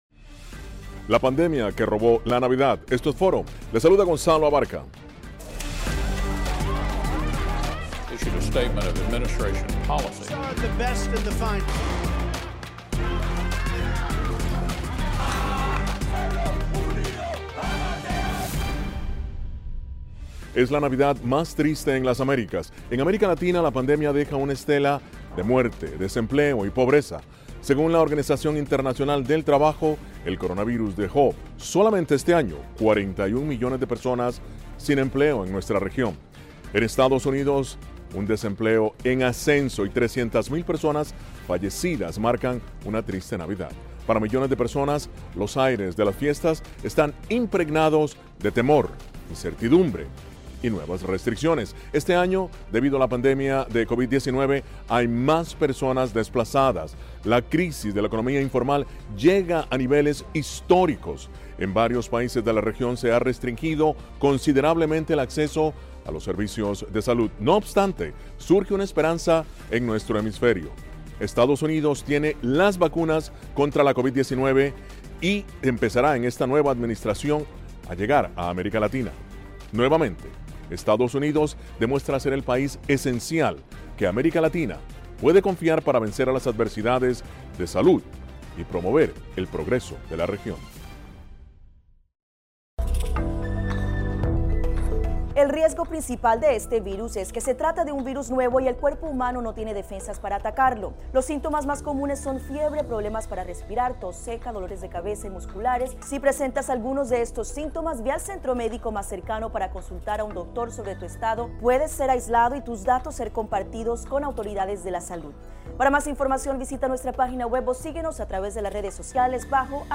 Analizan los economistas y expertos.